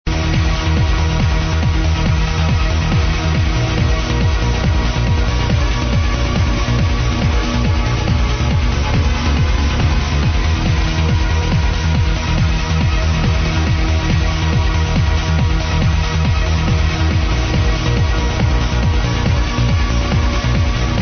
it was played at the party
german radio-dance-show